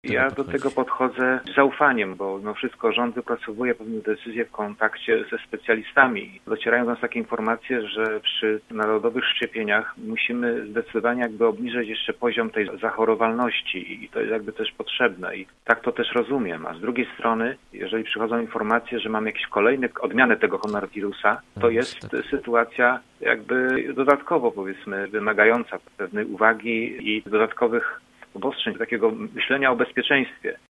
Walka z koronawirusem wymaga myślenia o bezpieczeństwie – tak o wprowadzaniu dodatkowych obostrzeń mówi Marek Budniak. Radny klubu Prawa i Sprawiedliwości był gościem Rozmowy po 9.